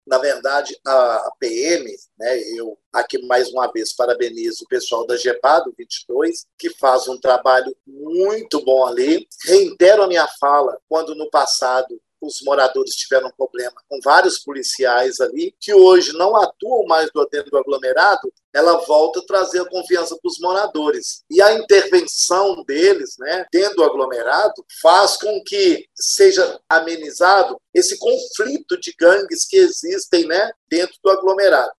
Vereador Gilson Guimarães, eleito pelo Aglomerado da Serra, sobre o Gepar